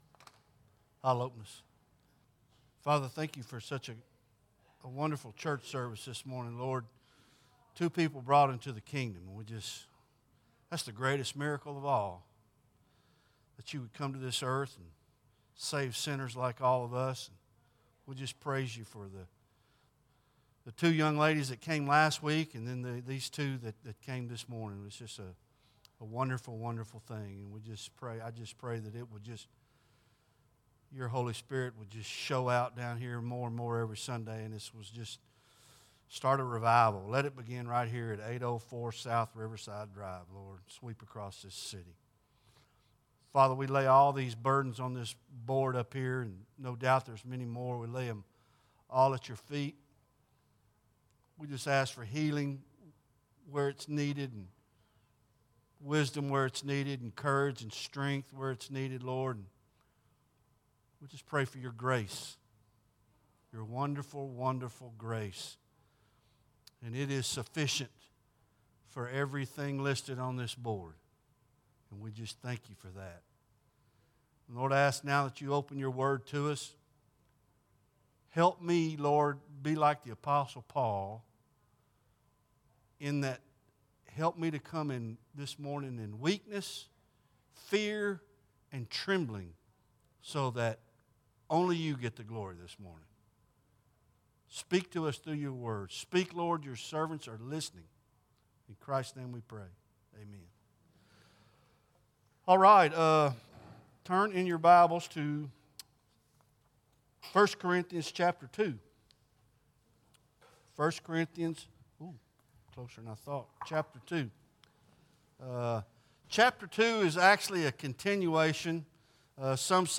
series: Sunday Morning Worship